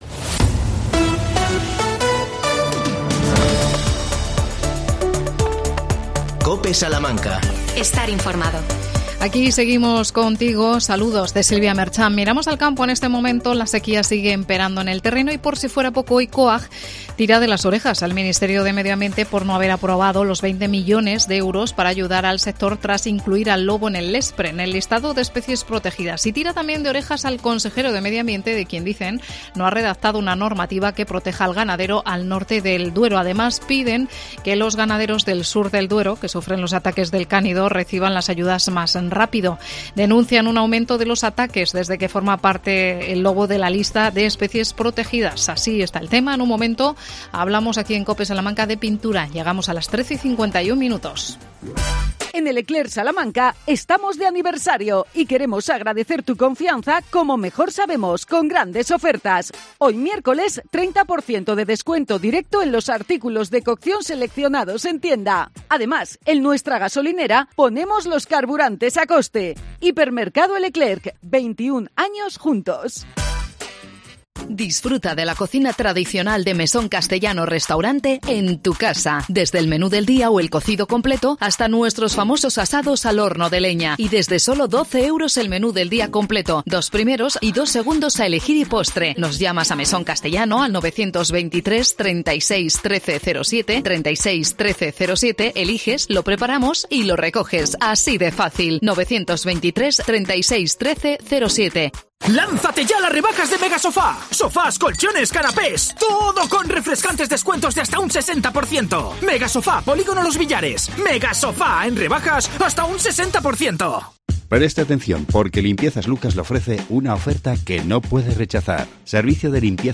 Arranca este viernes el Certamen de Pintura Mural del Medio Rural en Rágama Hablamos con su alcalde José Luis Moyano.